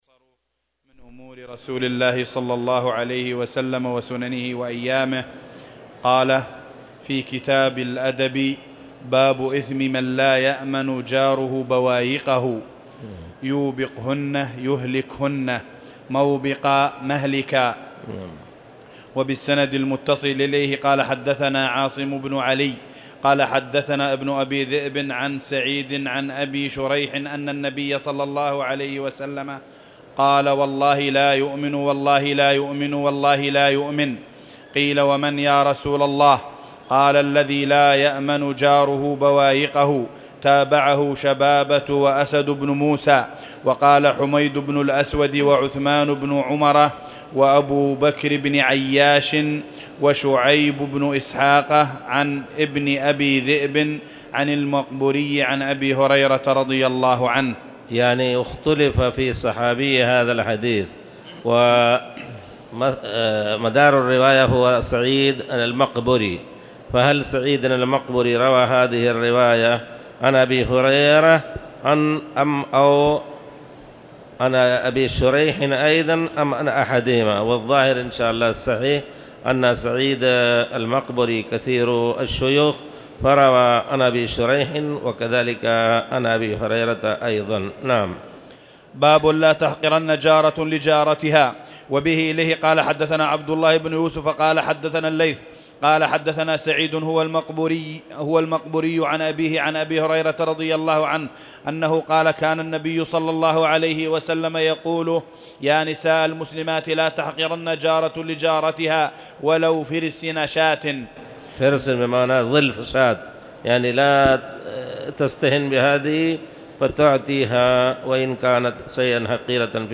تاريخ النشر ٩ ربيع الثاني ١٤٣٨ هـ المكان: المسجد الحرام الشيخ